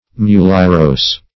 \Mu"li*er*ose`\